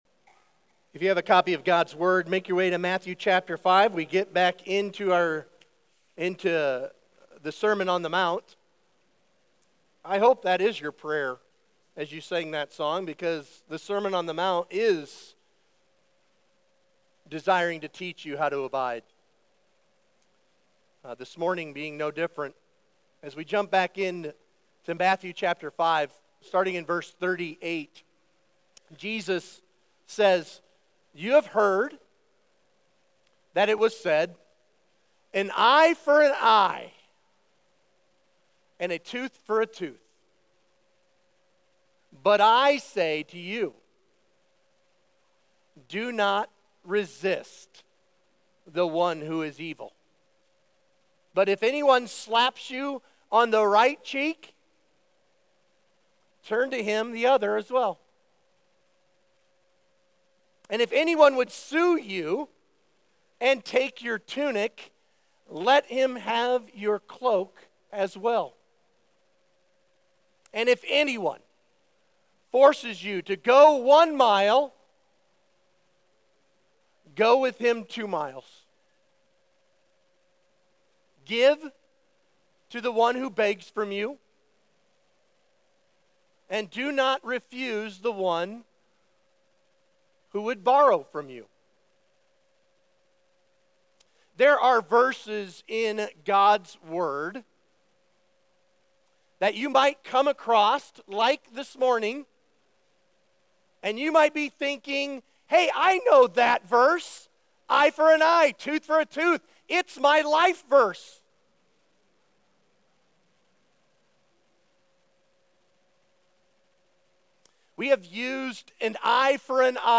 Sermon Questions Read Matthew 5:9 and Matthew 5:38-42. 1.